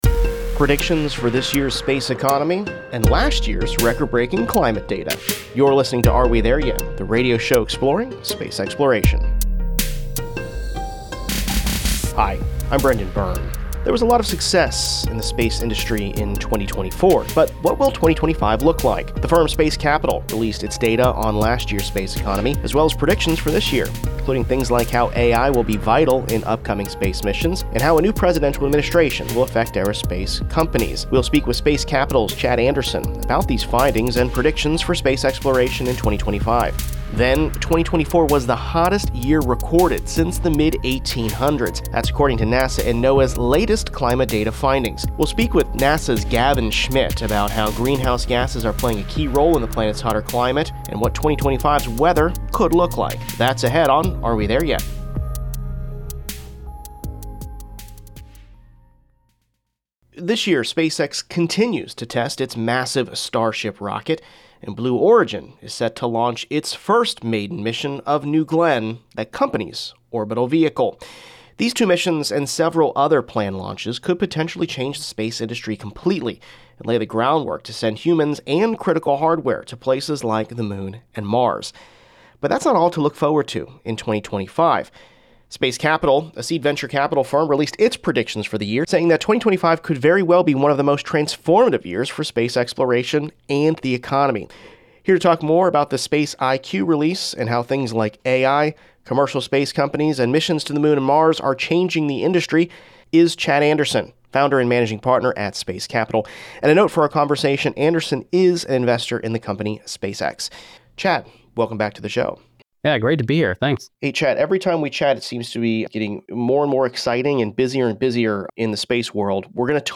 From efforts to launch humans into deep space, to the probes exploring our solar system, "Are We There Yet?" brings you the latest in news from the space beat. Listen to interviews with astronauts, engineers and visionaries as humanity takes its next giant leap exploring our universe.